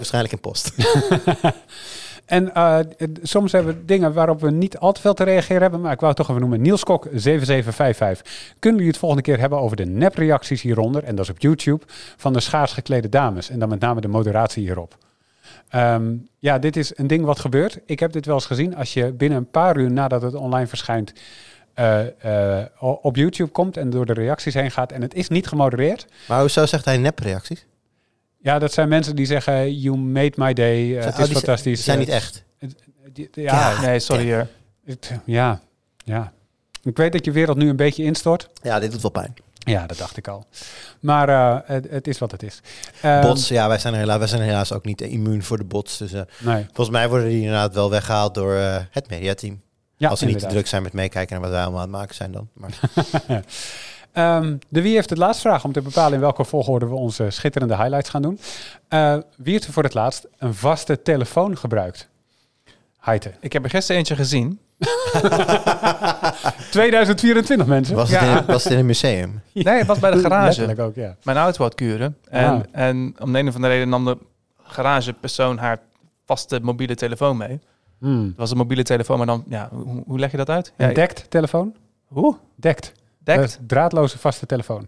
Ik hoor voor het eerst een piep in de opname.
in het geëxporteerde stukje hoor ik de piep niet
piep.mp3